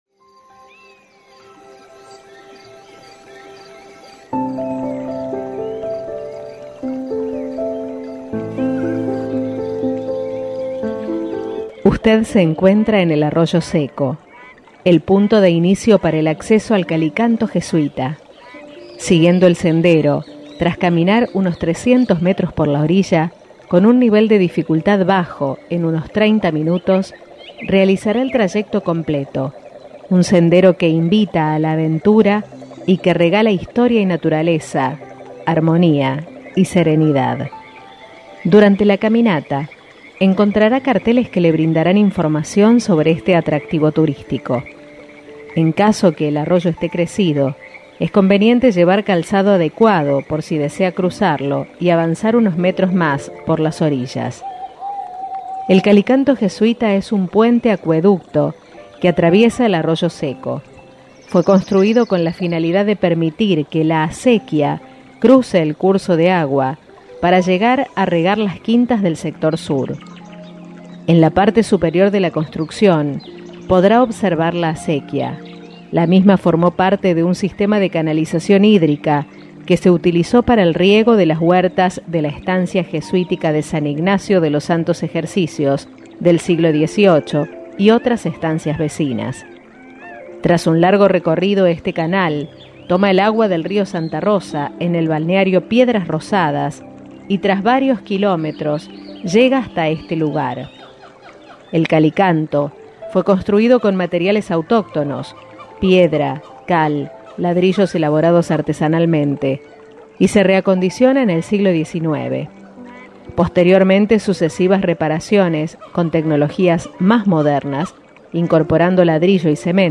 Conocé el Calicanto a través de nuestra guía digital